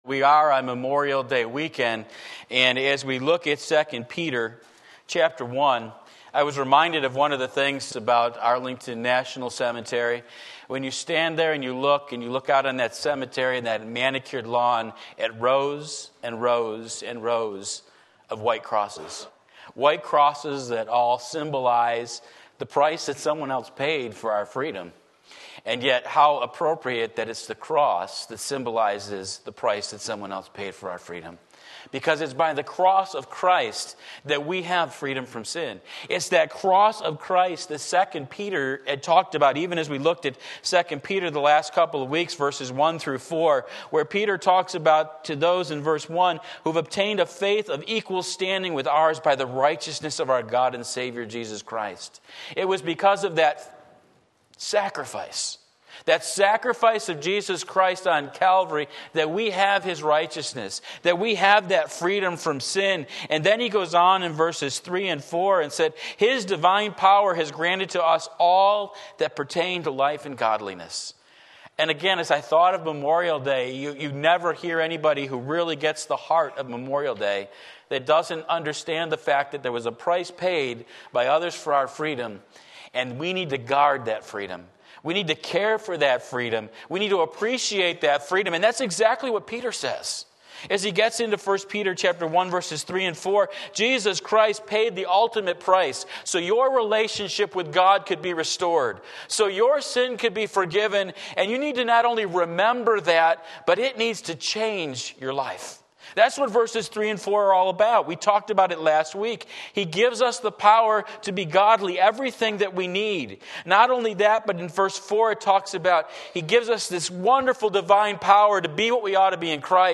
Sermon Link
Faith Is Not Enough 2 Peter 1:5-7 Sunday Morning Service, May 26, 2019 Stirred Up!